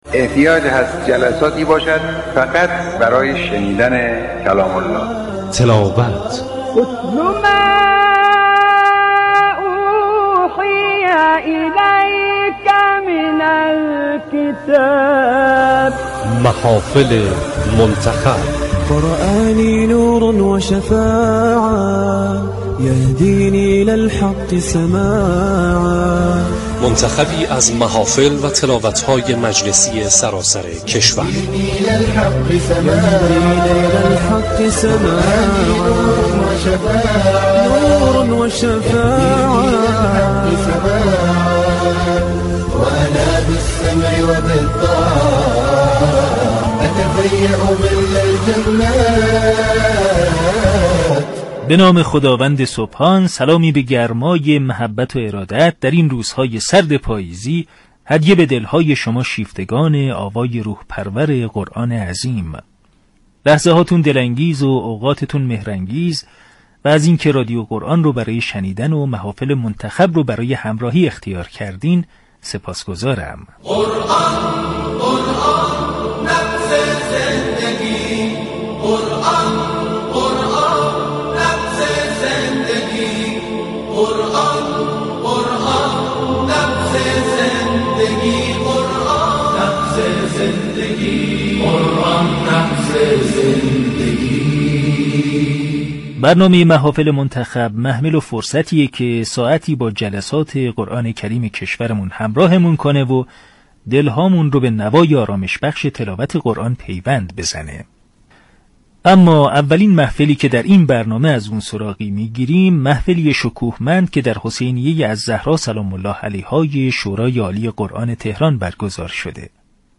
محفل منتخب هفته عنوان برنامه هفتگی رادیو قرآن است كه جمعه هر هفته ساعت 19 به انعكاس و پوشش محافل قرآنی برگزار شده در سراسر كشور می پردازد.